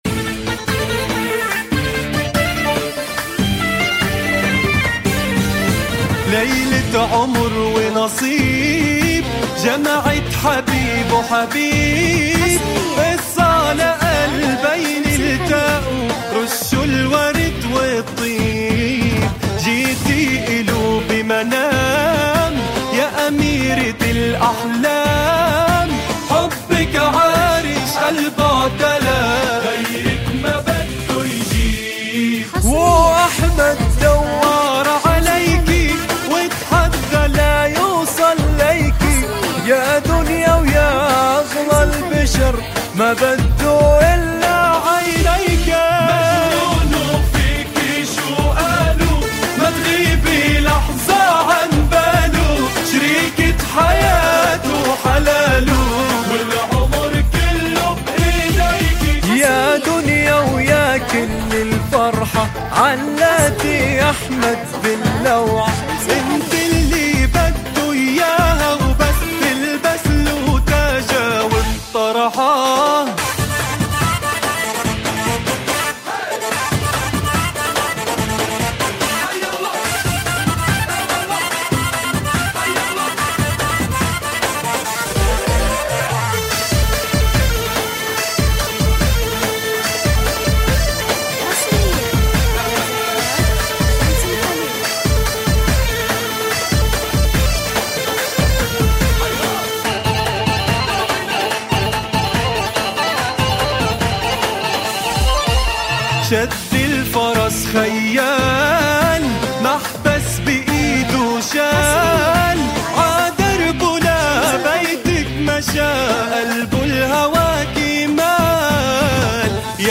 زفات موسيقى – زفات كوشة